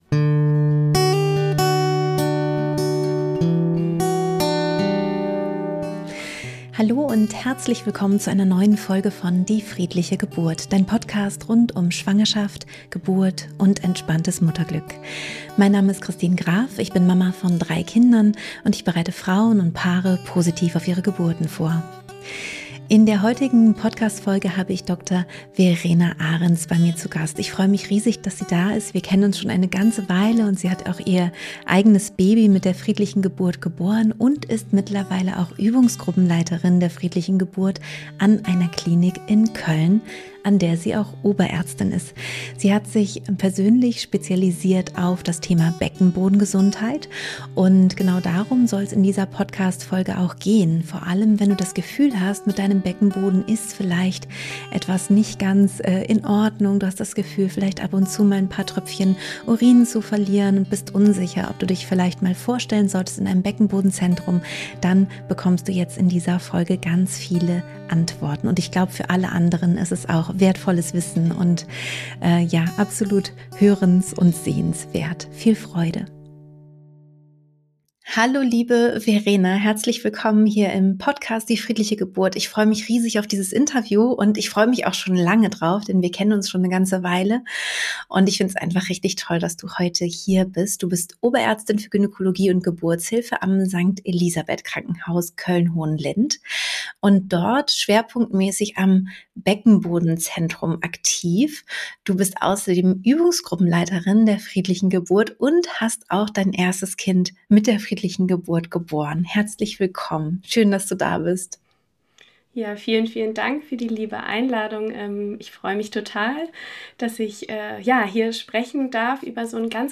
415 – Gyn klärt auf zum Beckenboden – Interview